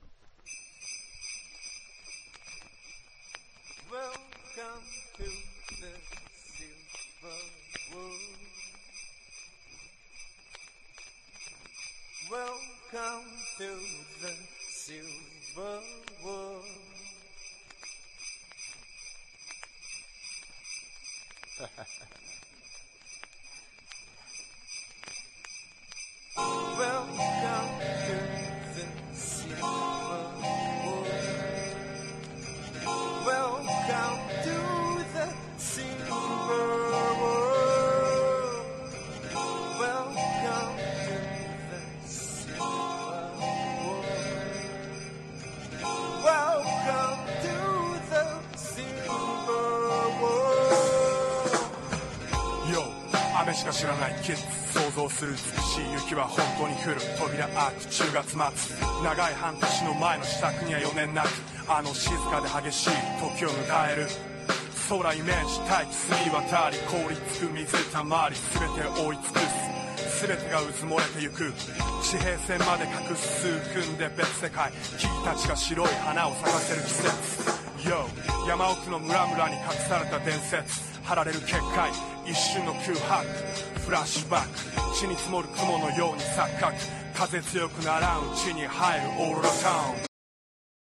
• HIPHOP